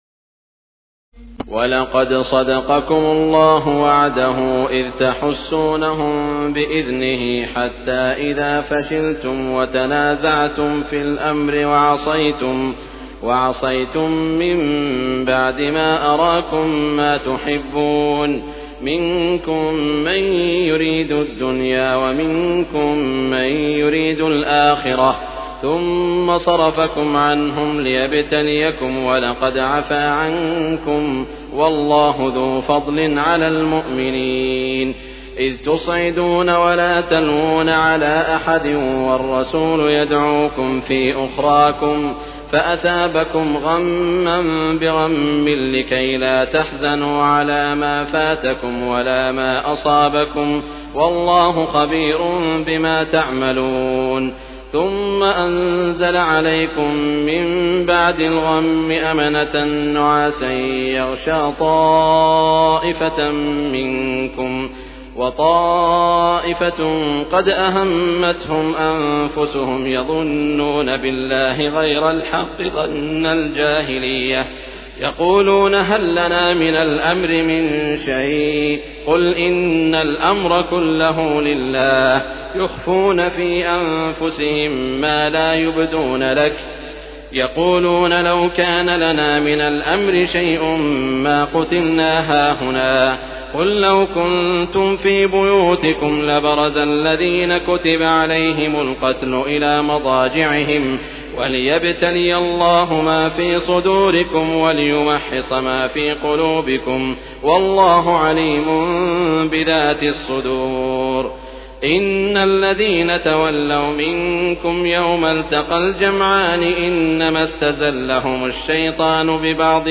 تراويح الليلة الخامسة رمضان 1418هـ من سورتي آل عمران (152-200) و النساء (1-16) Taraweeh 5 st night Ramadan 1418H from Surah Aal-i-Imraan and An-Nisaa > تراويح الحرم المكي عام 1418 🕋 > التراويح - تلاوات الحرمين